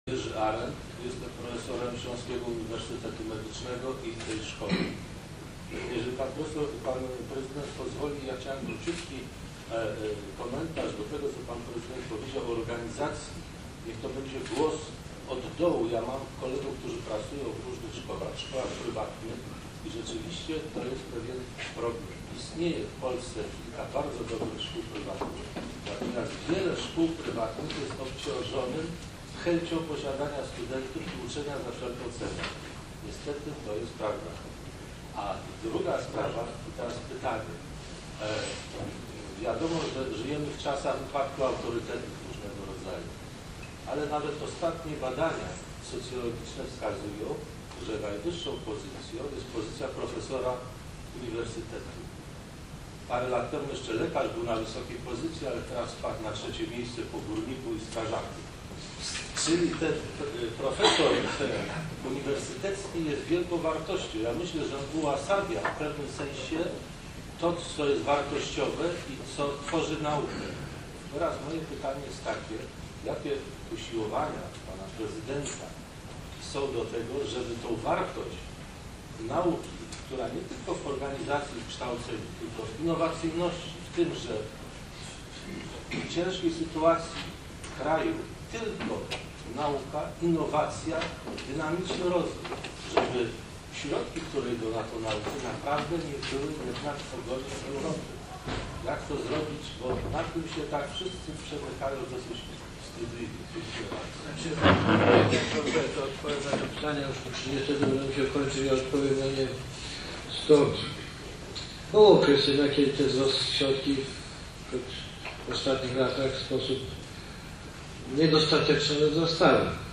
Po wykładzie Prezydent odpowiedział na pytania pracowników uczelni.